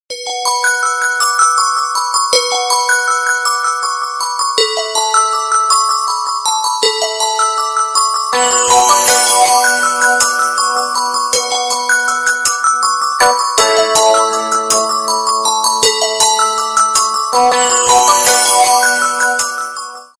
громкие рингтоны
Спокойные рингтоны
колокольчики